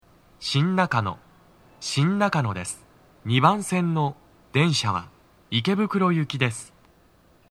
スピーカー種類 TOA天井型
2番線 池袋方面 到着放送 【男声